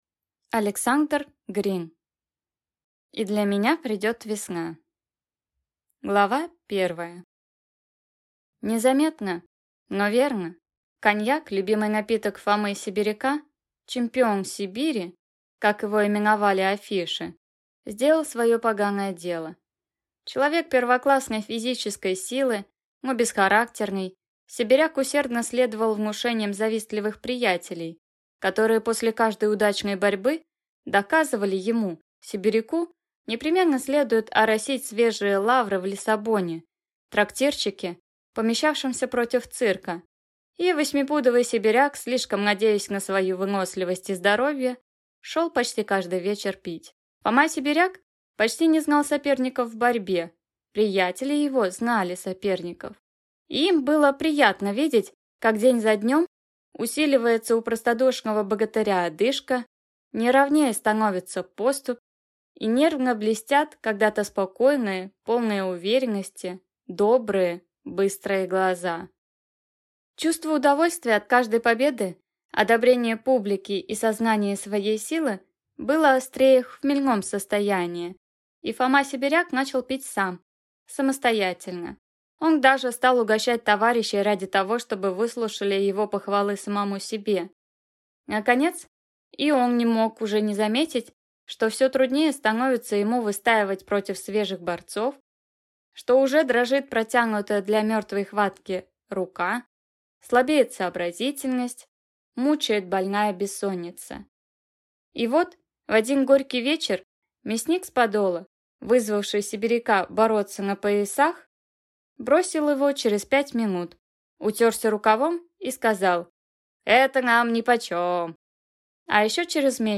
Аудиокнига И для меня придет весна | Библиотека аудиокниг